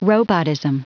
Prononciation du mot robotism en anglais (fichier audio)
Prononciation du mot : robotism